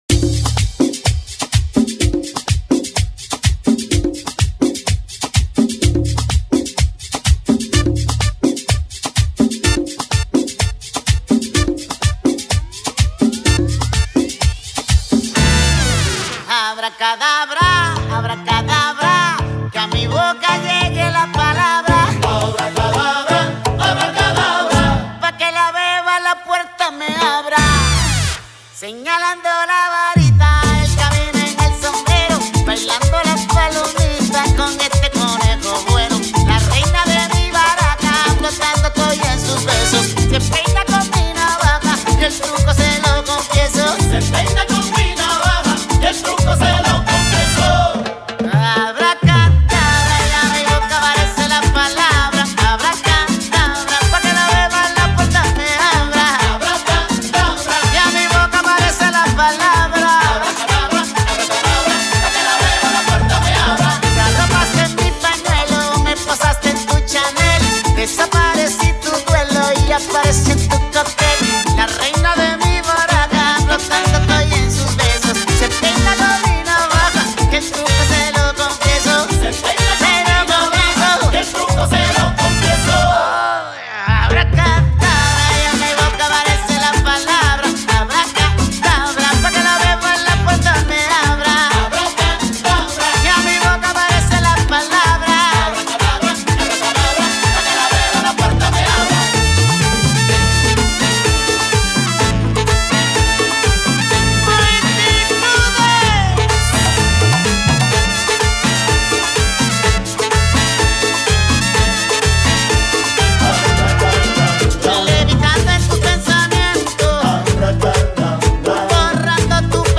Classic Merengue